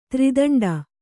♪ tri daṇḍa